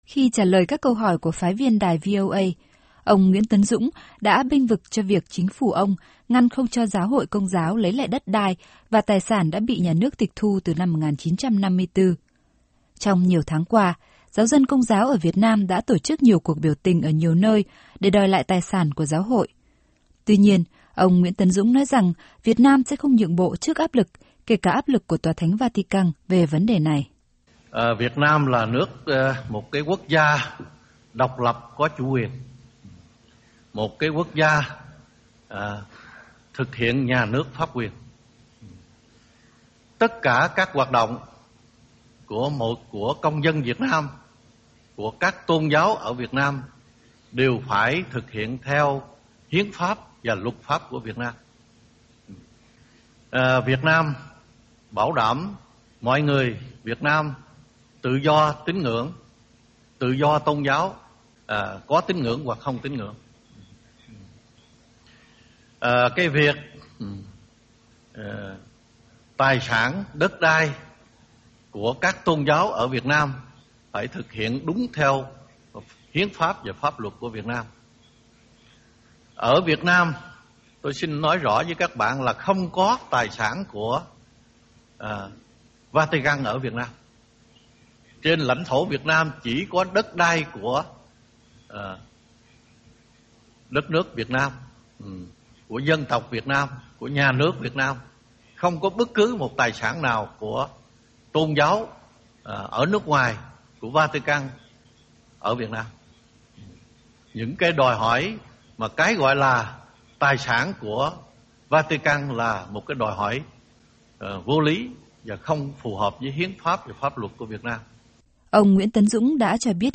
Phần thu thanh trong cuộc phỏng vấn của đài VOA
(*) Ghi chú của SH: Lời tuyên bố khi Thủ Tướng Nguyễn Tấn Dũng trả lời một số câu hỏi của đài VOA.